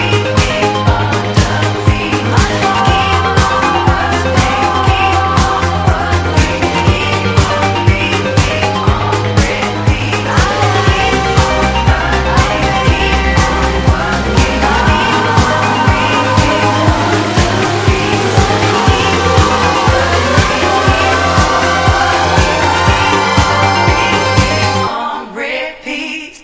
Electro / Krautrock / Alternative Synthpop Lp
Electro / Electronic Indie / Alternative Synthpop